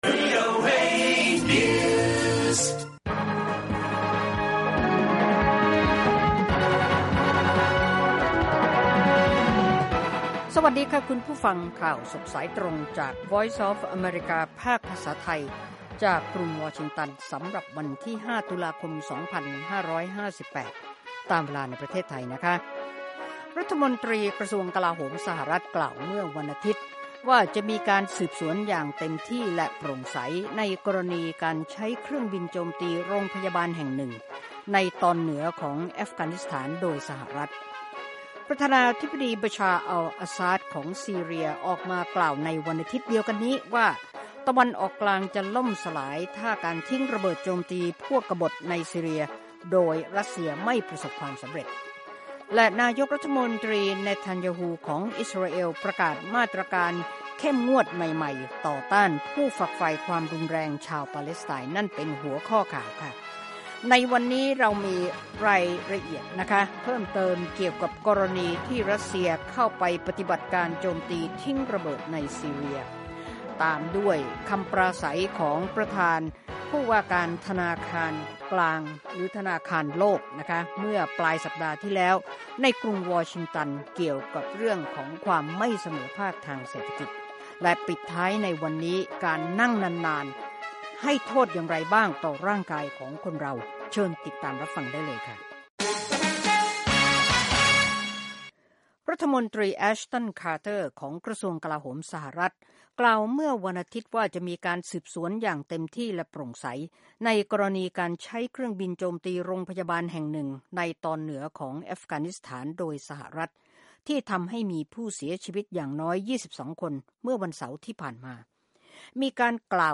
ข่าวสดสายตรงจากวีโอเอ ภาคภาษาไทย 6:30 – 7:00 น. วันจันทร์ 5 ต.ค. 2558